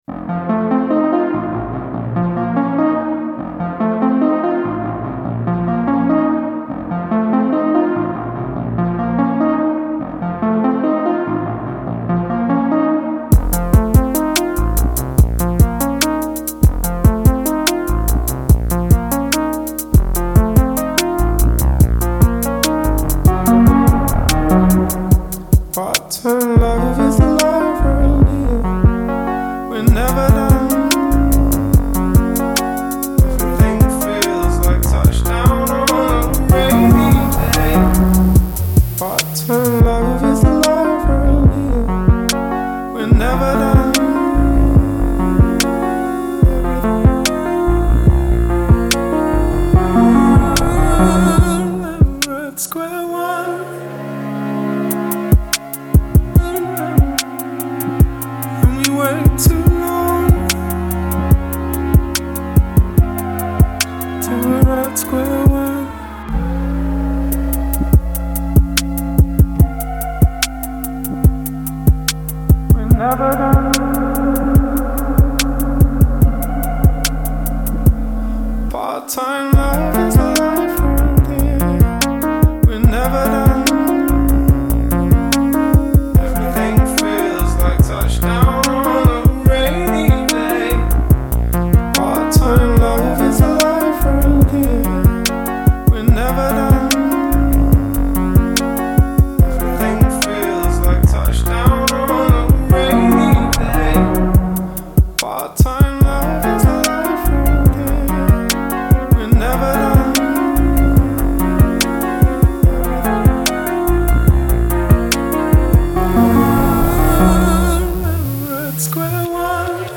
Beautiful and cold. Like winter.